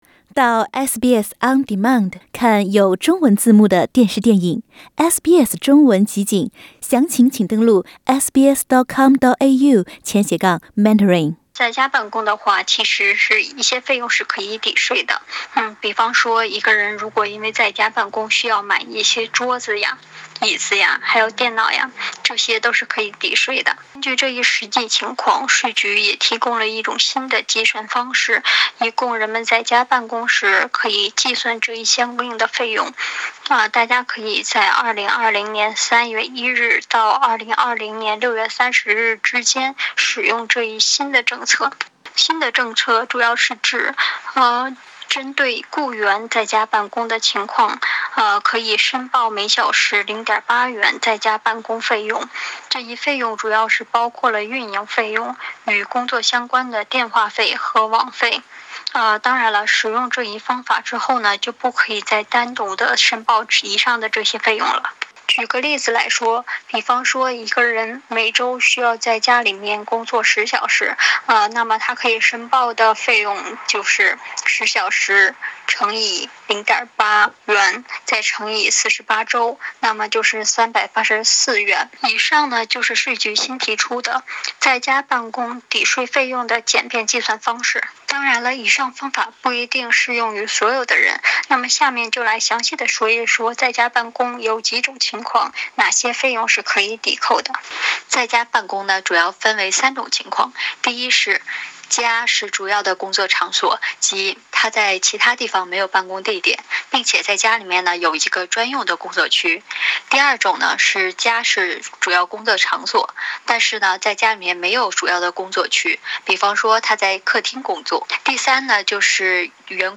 在新冠病毒疫情期间，政府为数以万计的临时在家工作的澳洲人，提供每小时0.8元扣税方法。 点击图片收听详细报道。